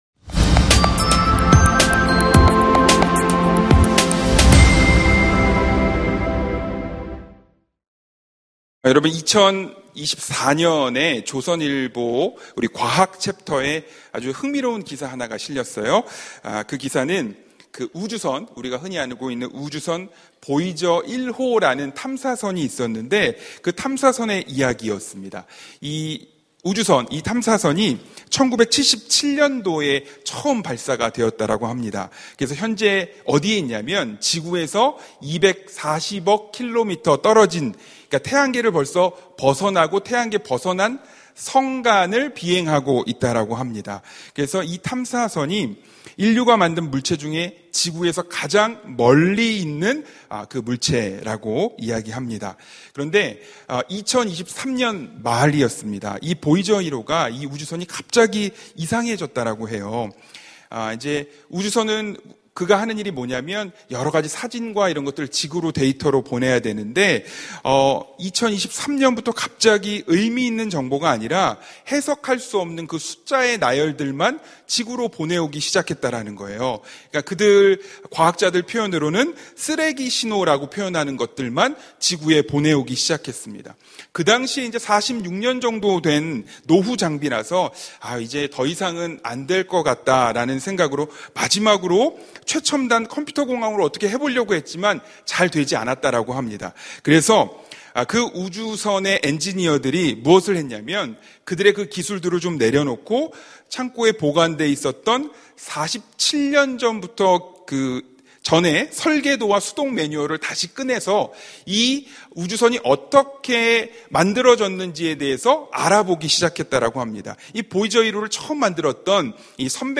금요심야기도회